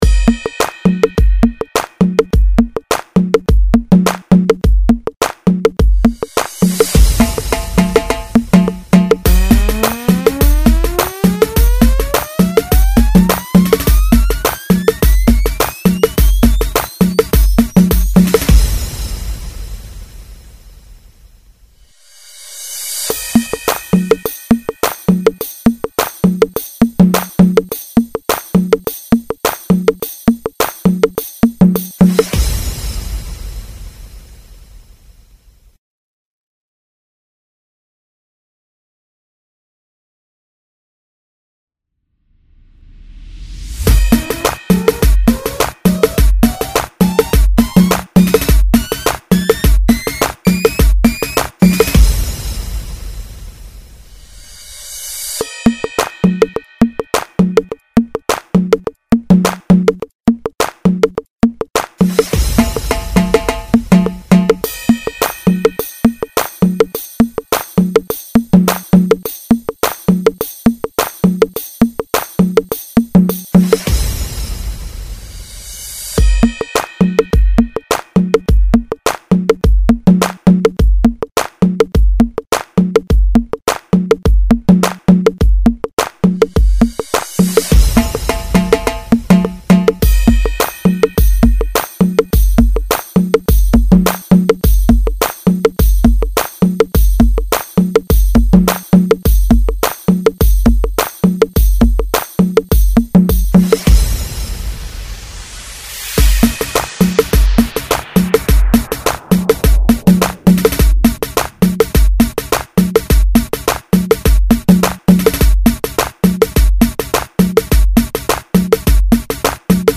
104 Beat